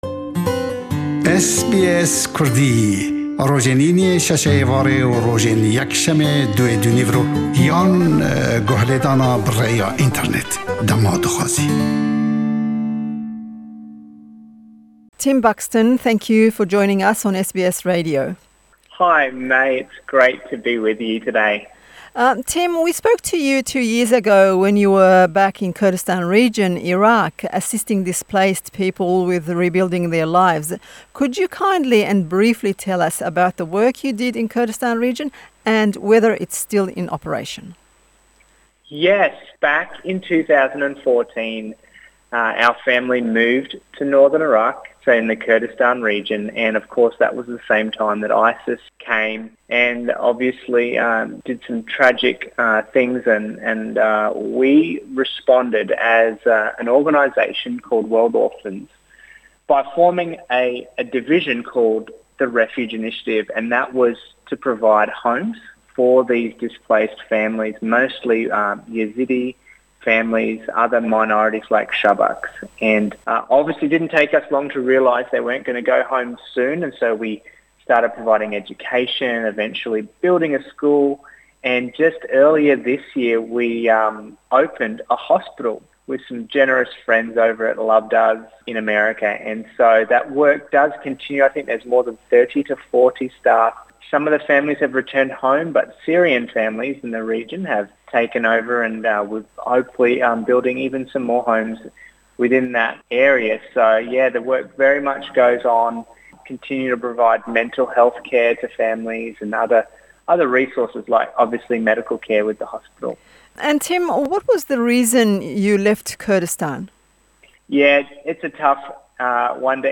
SBS Kurdish interviewed